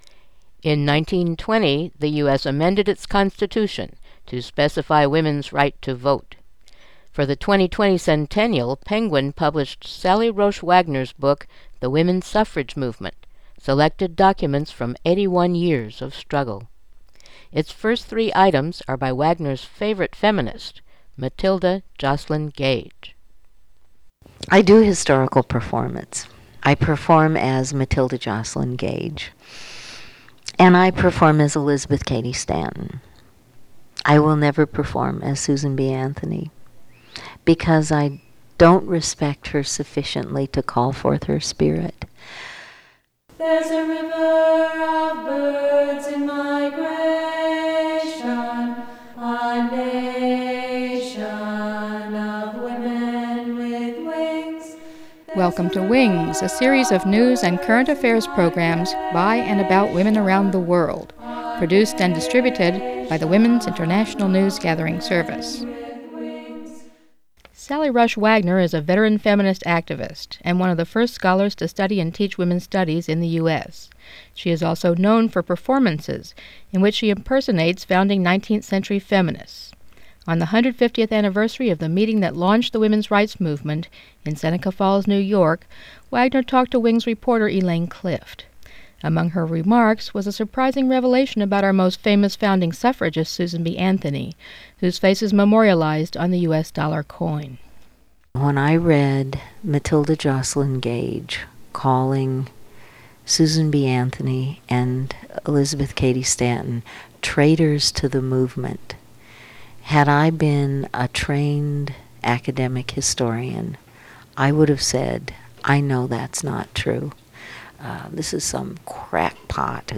Mono
interview